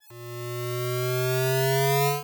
recharge.wav